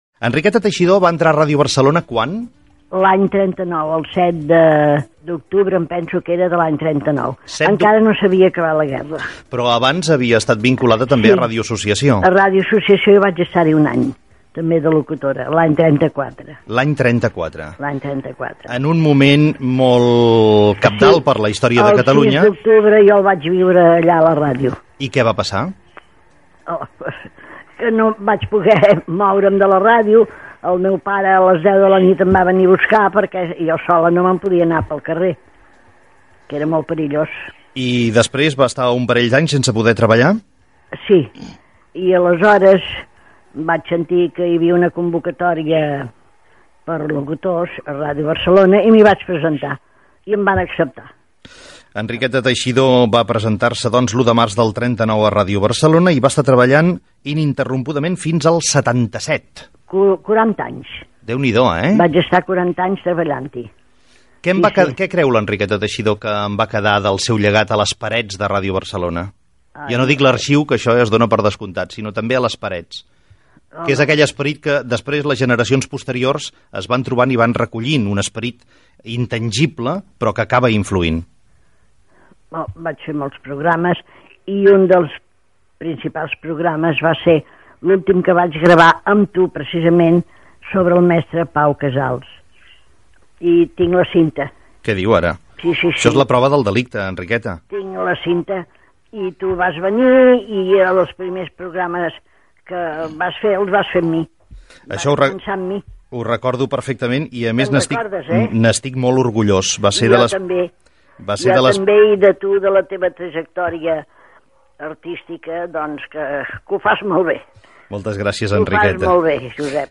Entrevistada per Josep Cuni - COM Ràdio, 1999